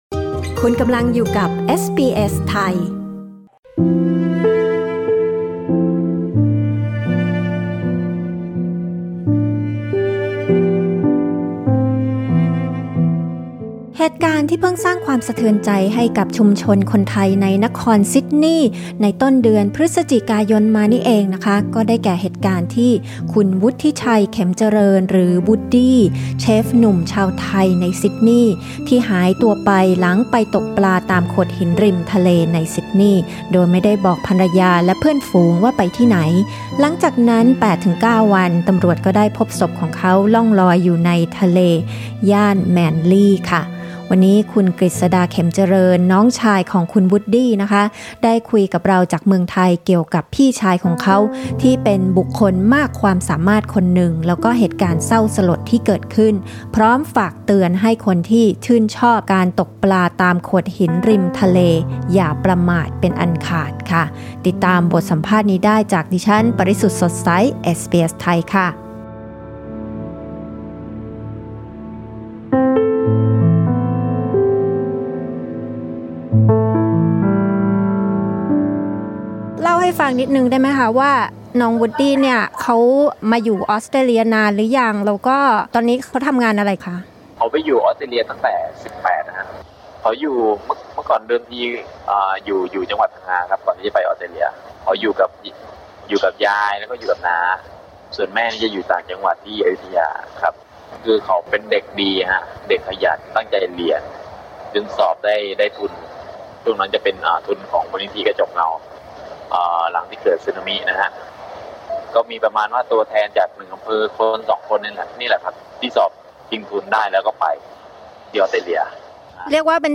ฟังการสัมภาษณ์เรื่องนี้ได้ที่นี่